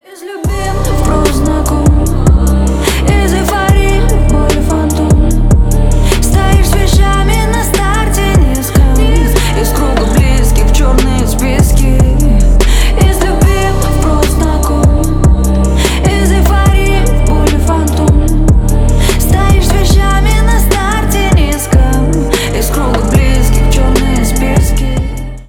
• Качество: 320, Stereo
грустные
медленные